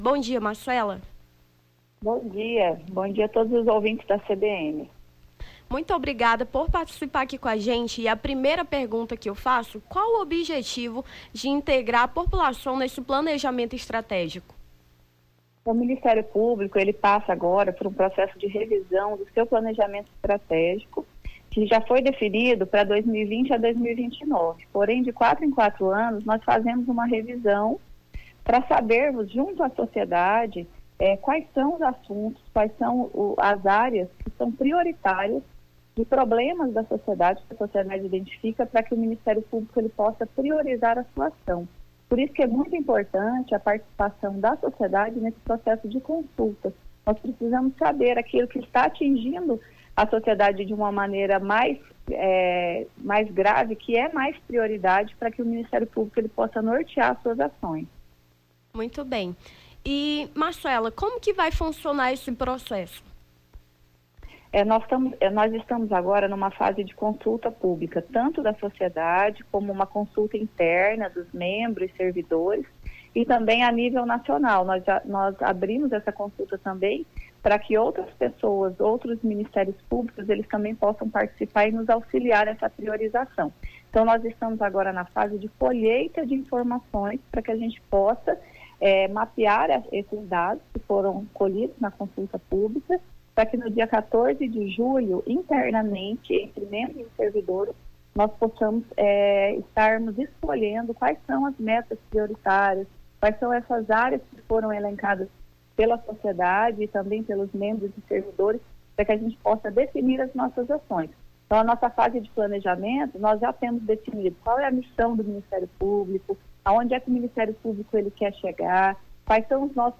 Nome do Artista - CENSURA - ENTREVISTA (PLANEJAMENTO ESTRATEGICO MPAC) 19-06-23.mp3